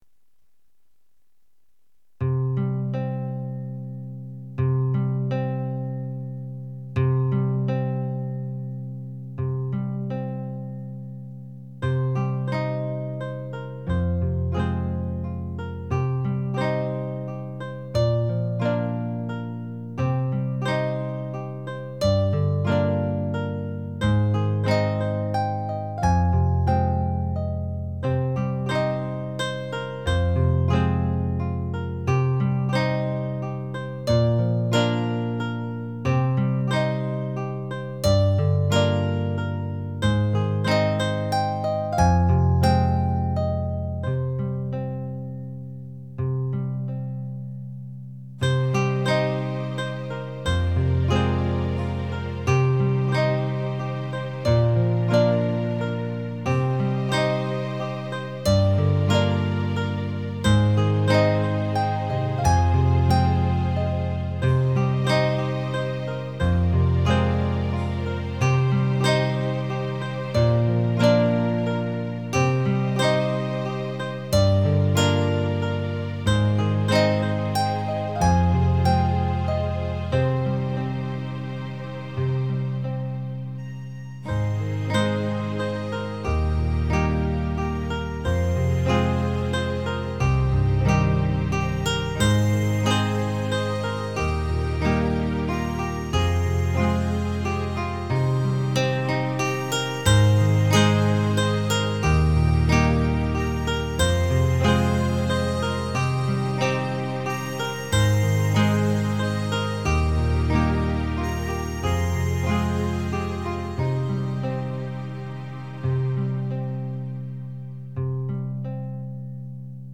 Relaxing guitar track with strings and a harmonica.
• Music has an ending (Doesn't loop)